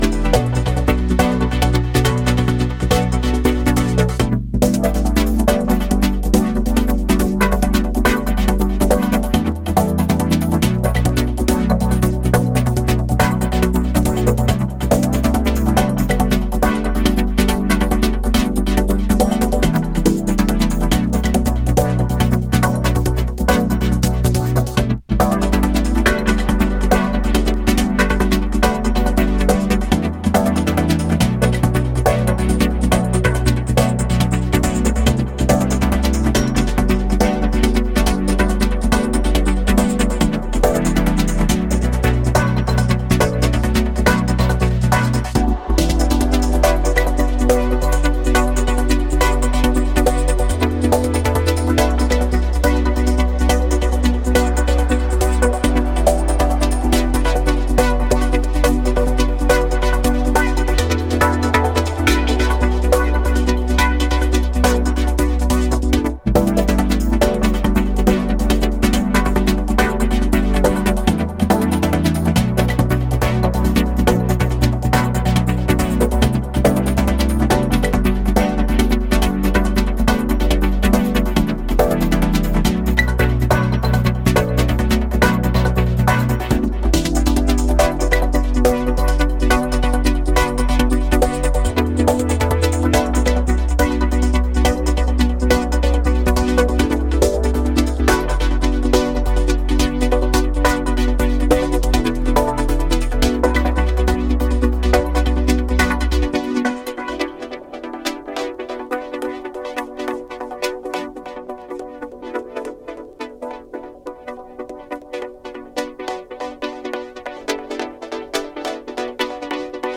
なんといっても殆どキックが入らないトランシーでエクスタティックな構成がたまらないです。